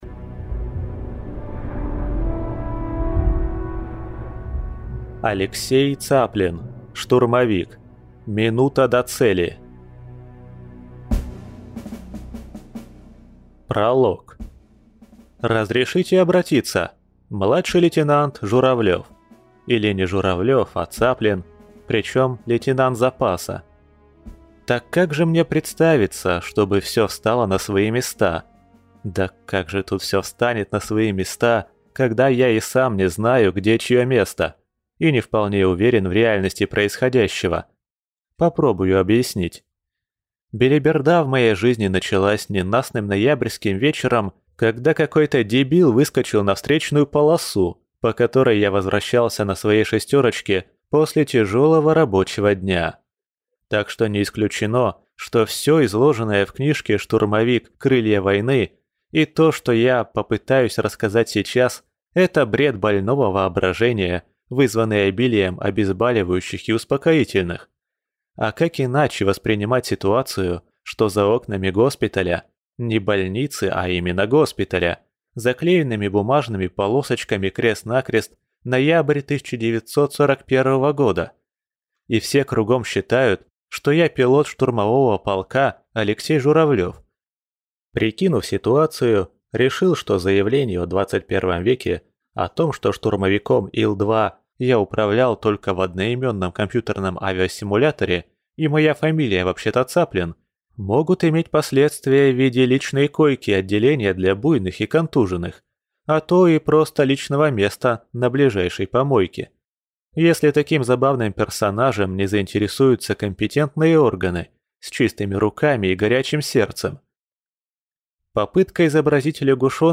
Аудиокнига Штурмовик. Минута до цели | Библиотека аудиокниг